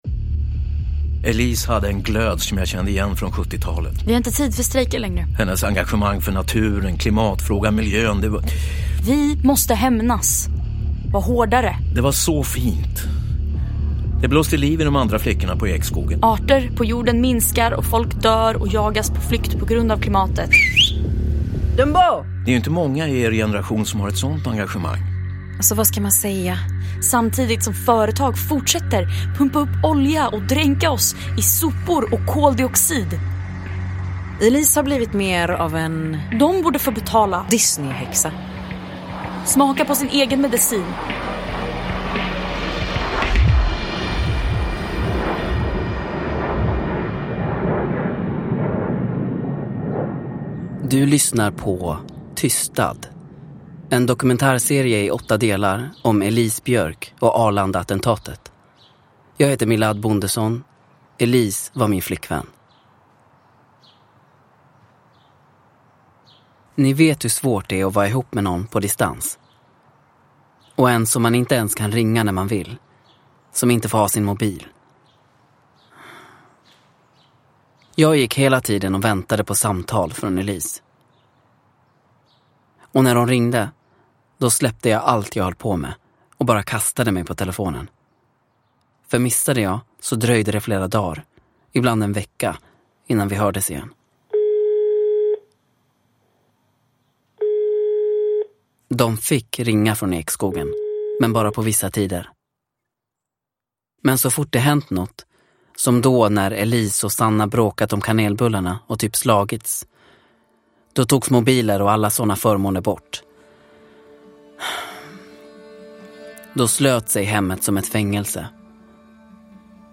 Tystad - 3 – Ljudbok – Laddas ner
TYSTAD är en fiktiv dokumentärserie av Åsa Anderberg Strollo och Lisa Bjärbo.